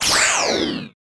powerPushObjects.wav